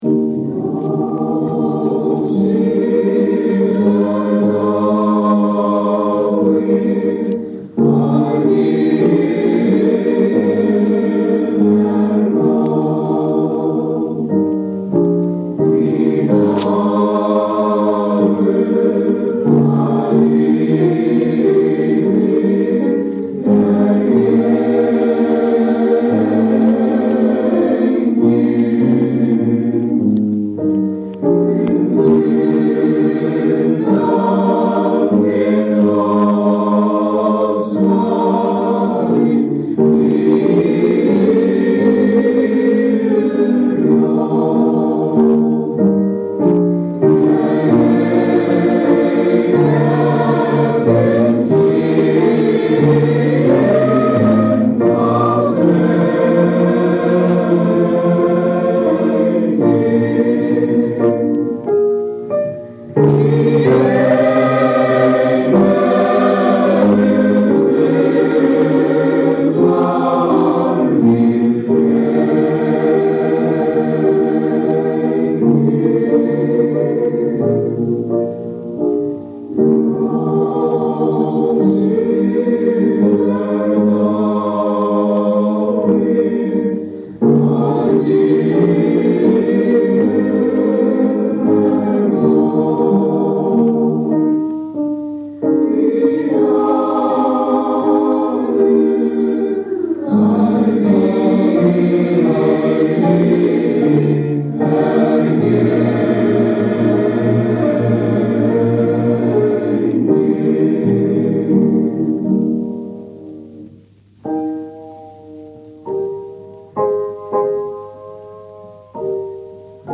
Upptaka frá æfingu 1951